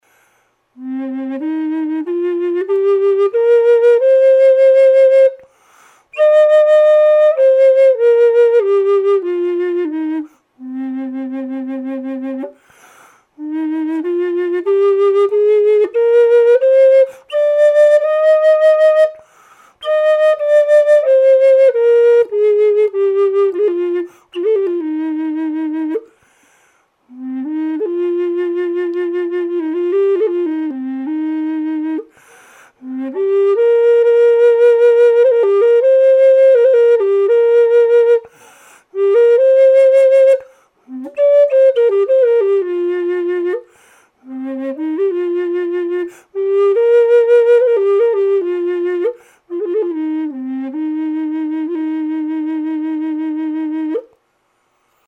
Bass Cm Side-Blown Mesquite Flute W/Spalted Tamarind Accents
Easy to play, great tone
This flute has a big, low tone.
mesquite_bass_cm_dry.mp3